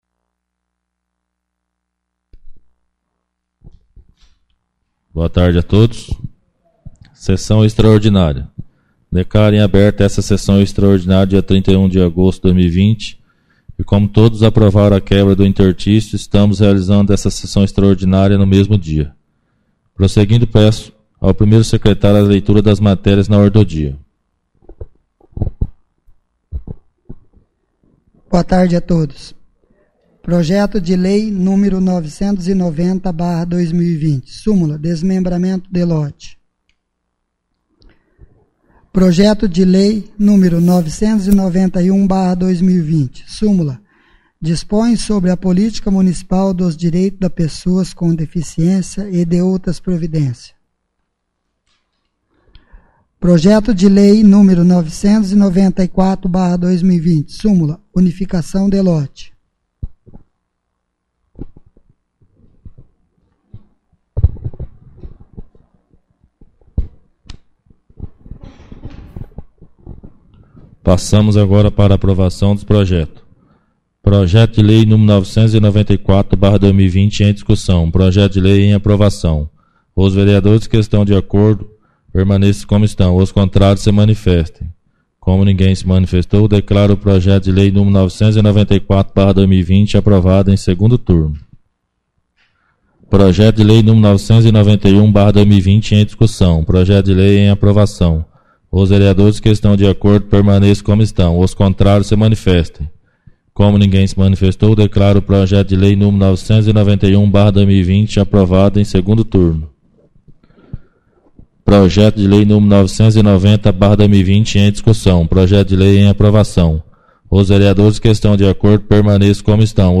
14ª Extraordinária da 4ª Sessão Legislativa da 11ª Legislatura